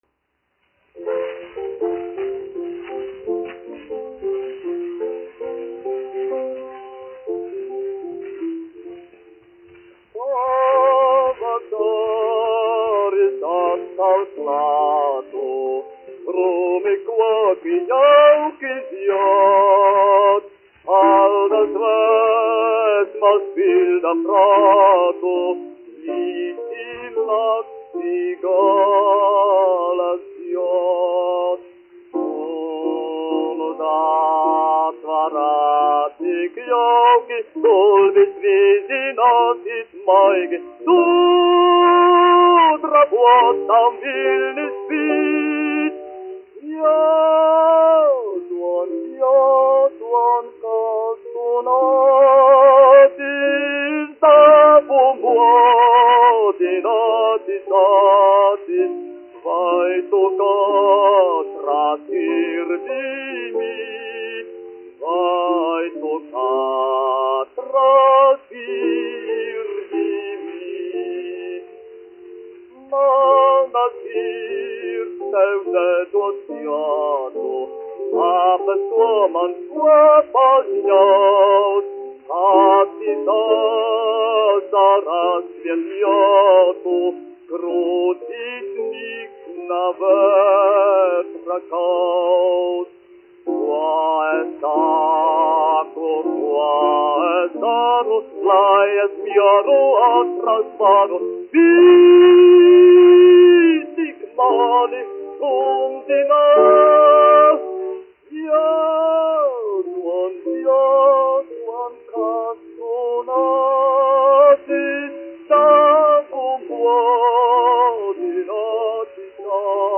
Jurjāns, Pāvuls, 1866-1948, dziedātājs
1 skpl. : analogs, 78 apgr/min, mono ; 25 cm
Dziesmas (vidēja balss) ar klavierēm
Skaņuplate
Latvijas vēsturiskie šellaka skaņuplašu ieraksti (Kolekcija)